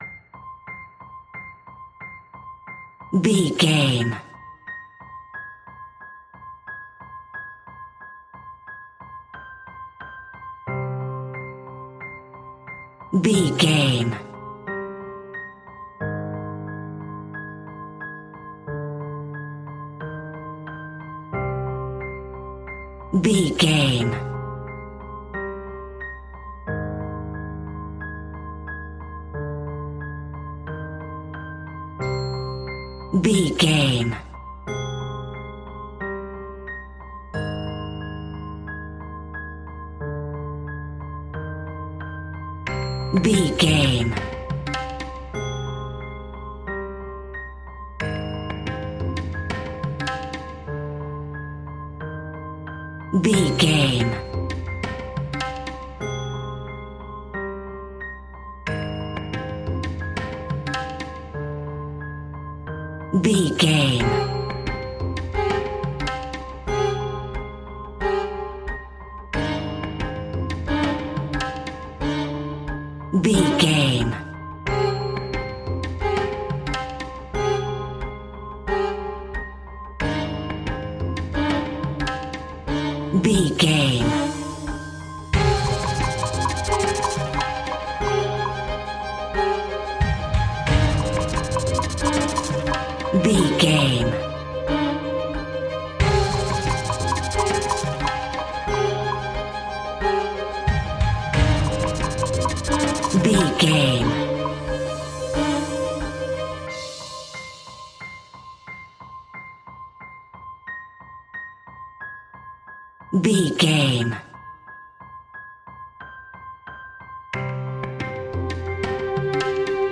Aeolian/Minor
scary
tension
ominous
dark
eerie
piano
percussion
synthesizer
horror music
horror piano